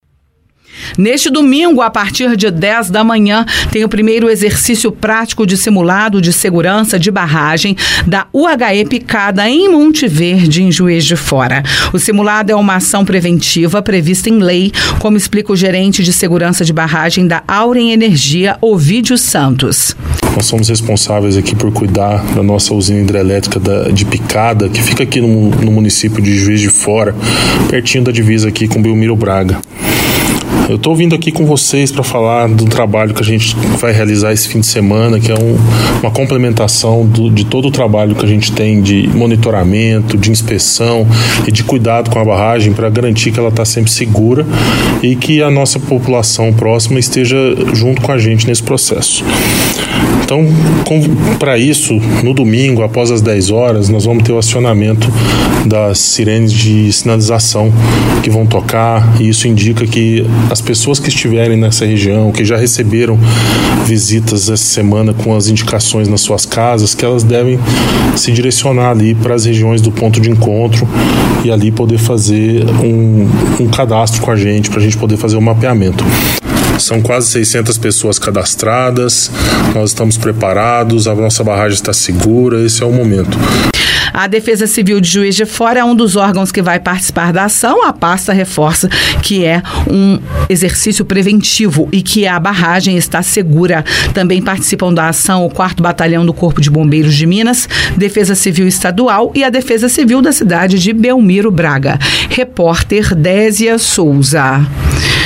De acordo com as autoridades e com os responsáveis pela UHE Picada, a barragem está segura e o procedimento previsto para começar às 10h é para orientar moradores. Ouça a reportagem